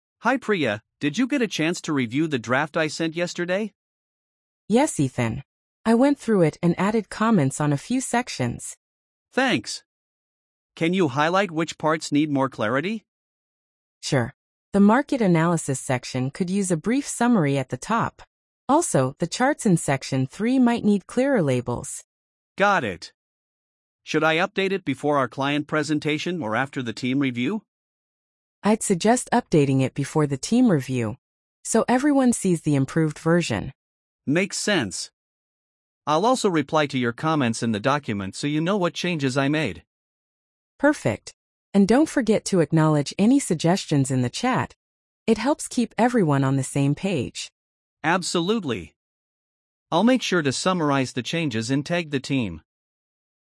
🤝 Two colleagues discuss feedback on a document draft.